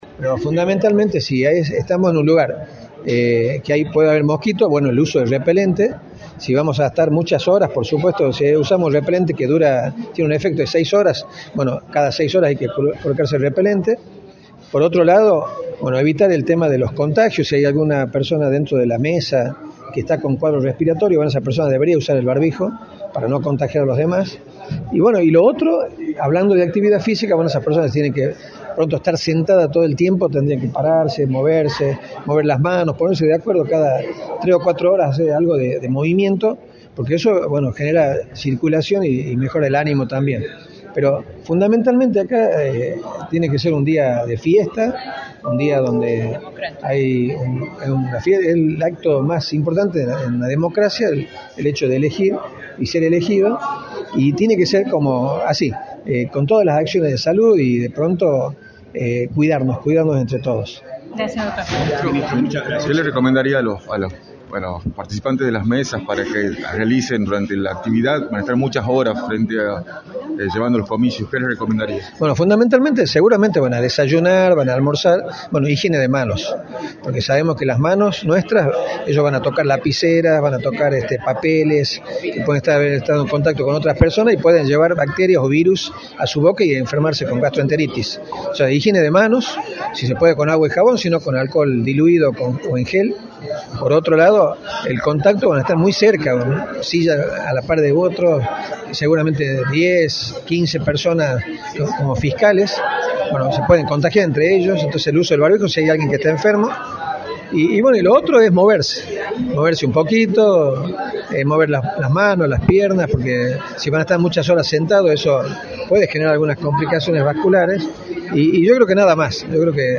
Luis Medina Ruíz, Ministro de Salud, informó en Radio del Plata Tucumán, por la 93,9, los consejos de salud para las autoridades de mesa y los fiscales que participen de las elecciones del próximo domingo.